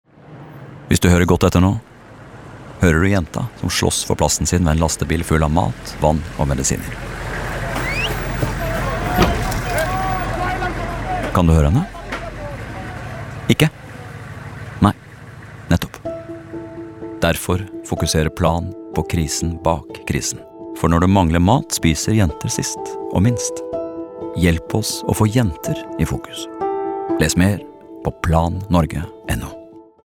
Månedens vinner evner å dra oss lenger inn i radiohøyttaleren for hver gang, og vi regner med at flere lyttere vil spille dem om igjen om de kunne.